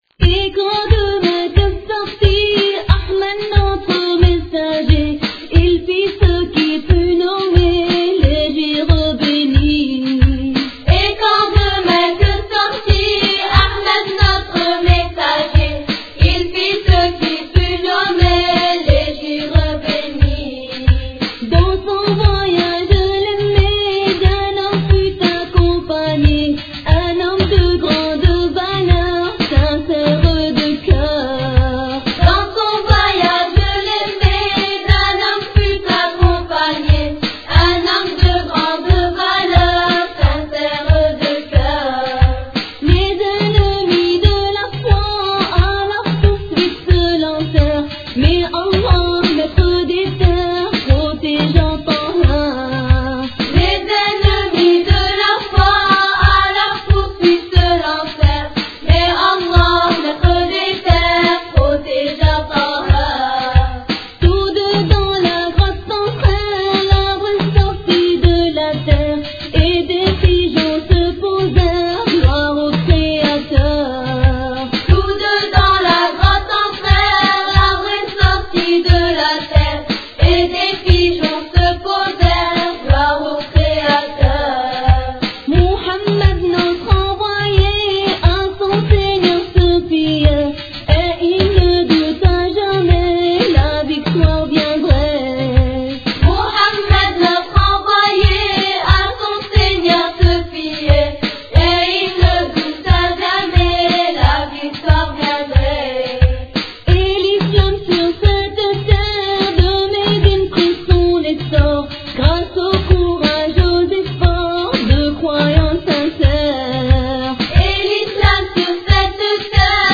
Chant réalisé par des soeurs
Attention ! chants de soeurs spécial Aïd !